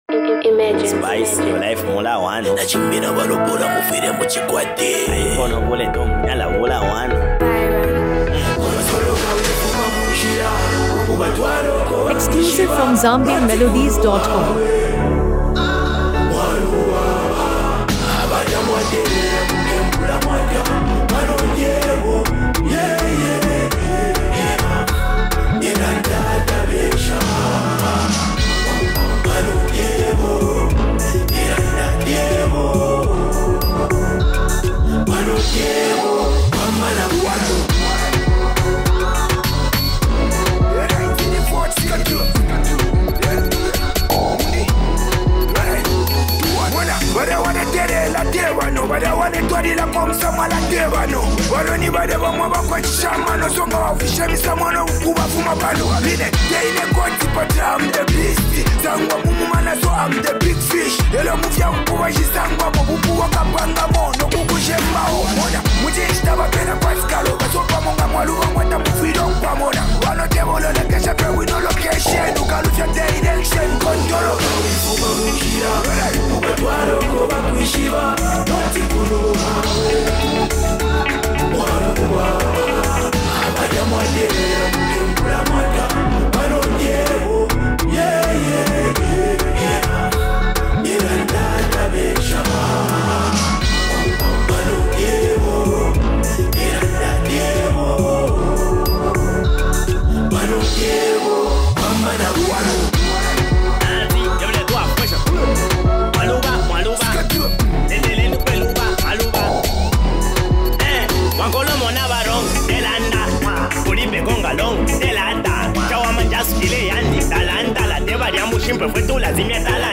high-energy club anthem
with fans praising its catchy hook, heavy beat